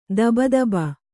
♪ daba daba